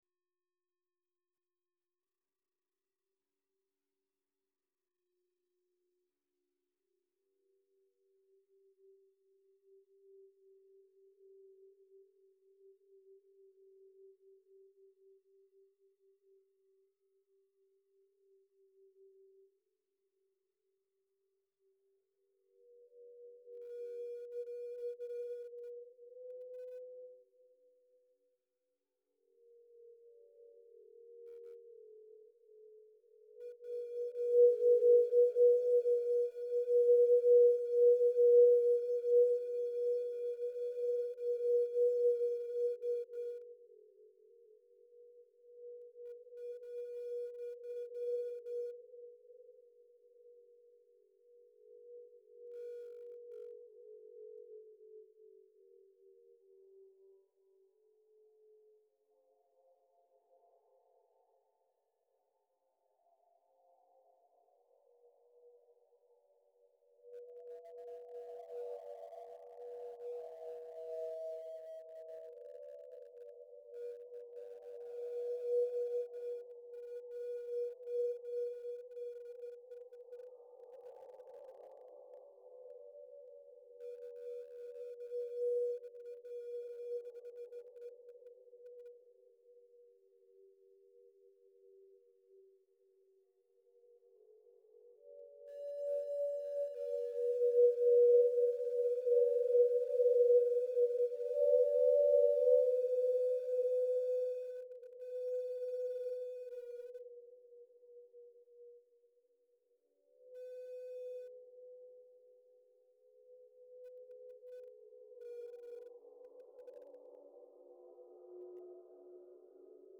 then, from 7.30pm onwards, performances by all three artists.
(binaural recordings—designed specifically for headphone listening)